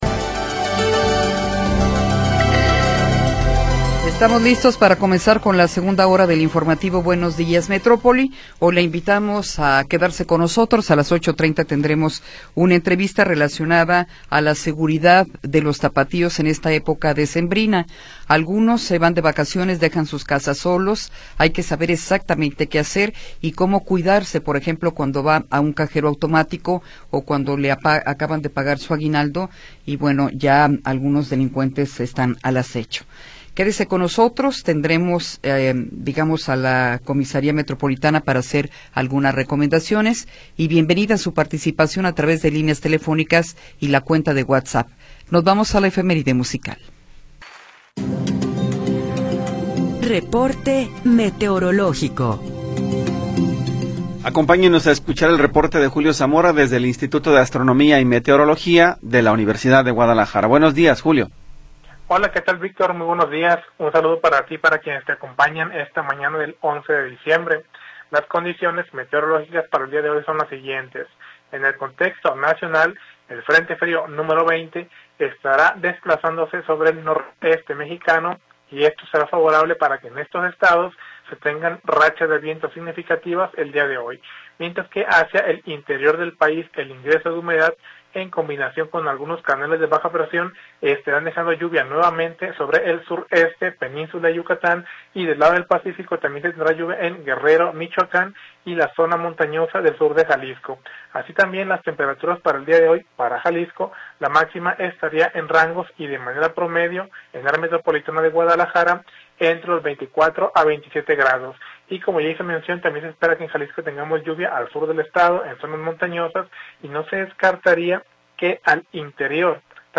Segunda hora del programa transmitido el 11 de Diciembre de 2025.